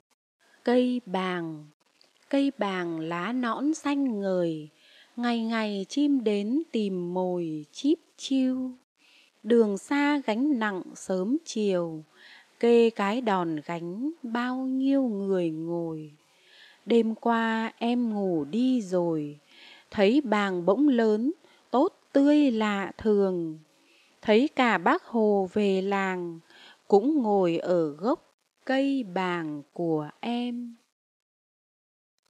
Sách nói | Bài thơ "Cây bàng"